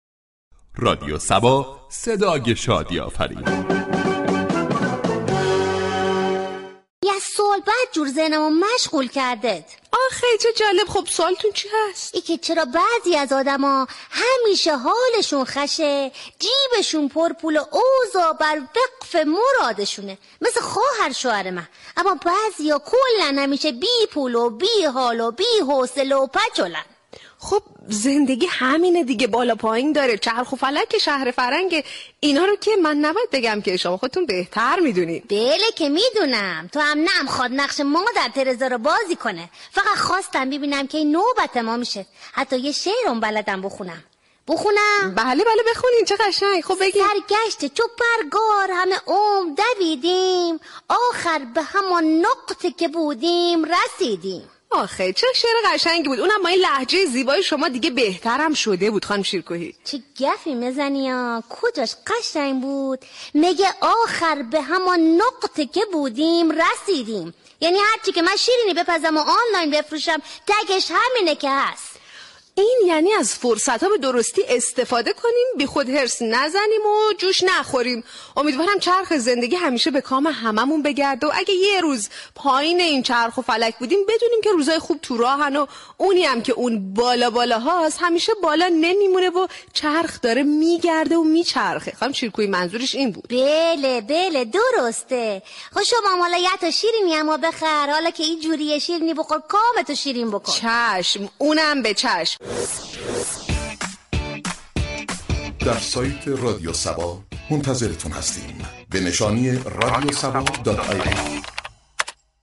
در بخش نمایشی شهر فرنگ با بیان طنز به موضوع فاصله طبقاتی پرداخته شده است ،در ادامه شنونده این بخش باشید.